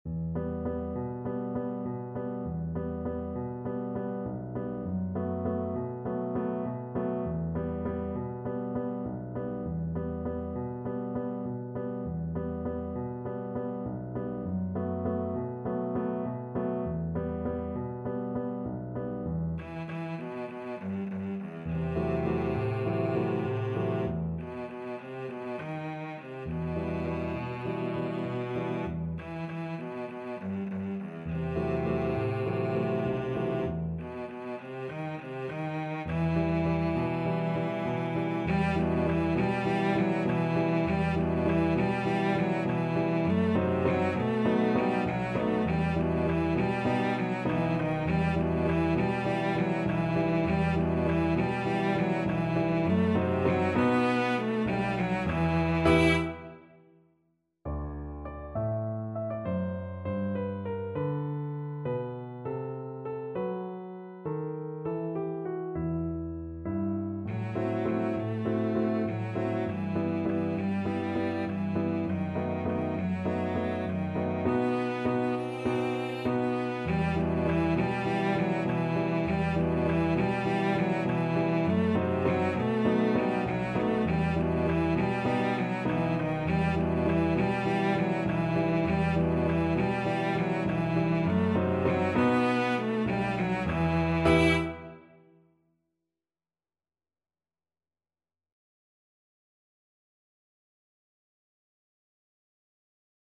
A sultry and atmospheric piece.
8/8 (View more 8/8 Music)
Moderato
World (View more World Cello Music)